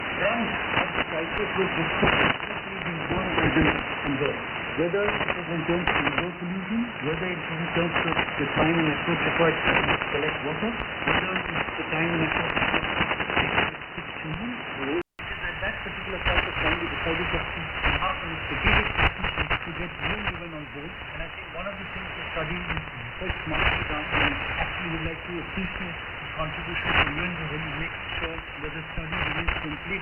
Für den Vergleich habe ich Audioaufnahmen der beiden Empfänger gemacht.
Sekunde 0-15 >> SDRplay RSPduo
Sekunde 15-30 >> Winradio G33DDC Excalibur Pro